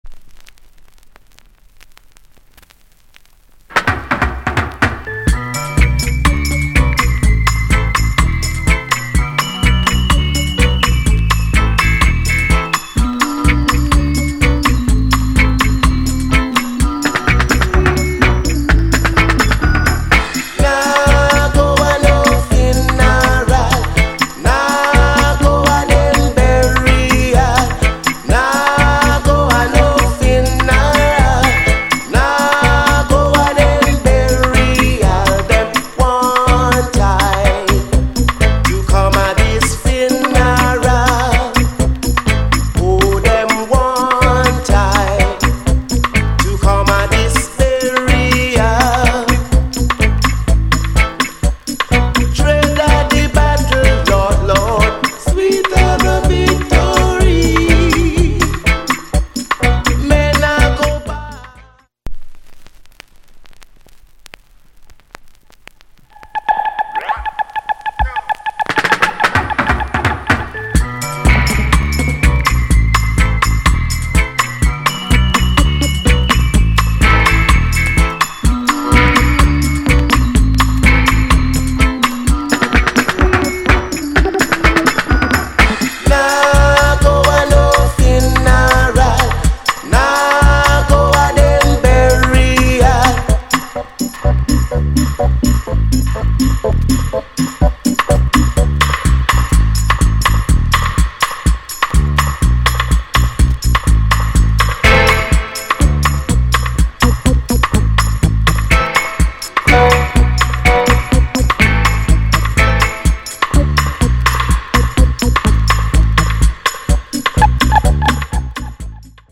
よりキックとベースが強調されたロッカーズ・カット。